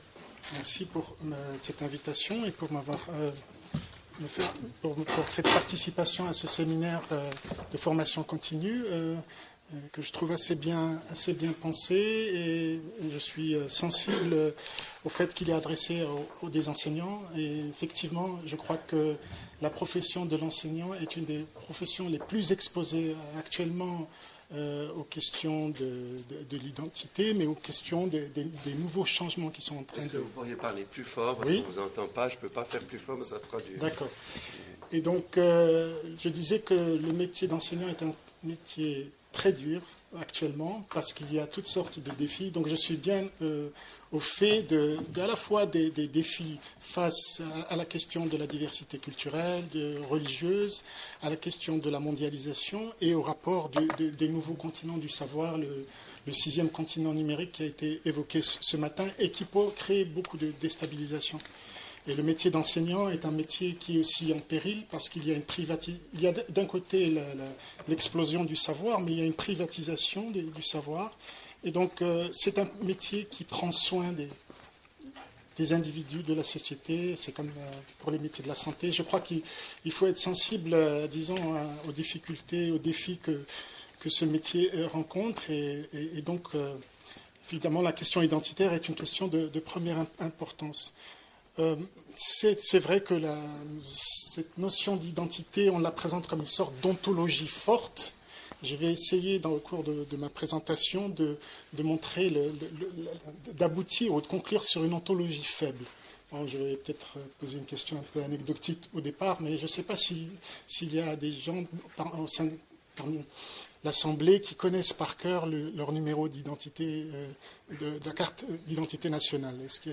Exposé